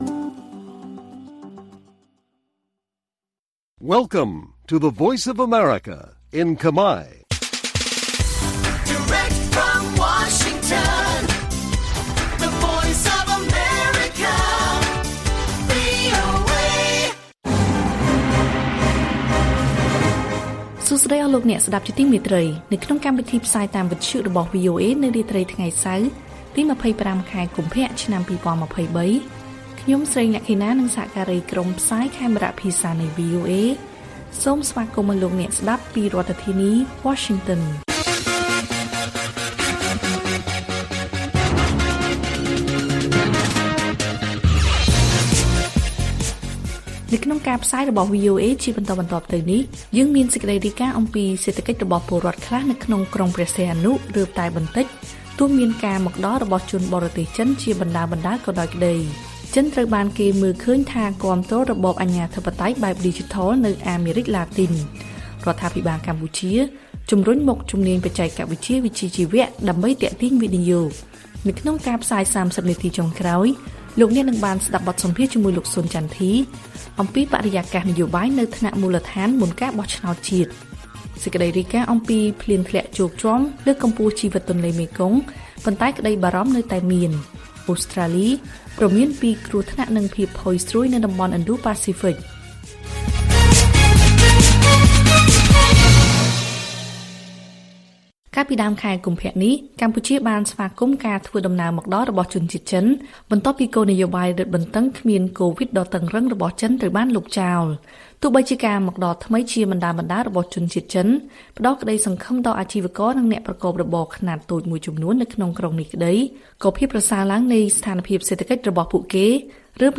ព័ត៌មានថ្ងៃនេះមានដូចជា សេដ្ឋកិច្ចរបស់ពលរដ្ឋខ្លះក្នុងក្រុងព្រះសីហនុរើបតែបន្តិច ទោះមានការមកដល់របស់ជនបរទេសចិនជាបណ្តើរៗក្តី។ បទសម្ភាសន៍អំពីបិរយាកាសនយោបាយនៅថ្នាក់មូលដ្ឋានមុនការបោះឆ្នោតជាតិ និងព័ត៌មានផ្សេងៗទៀត៕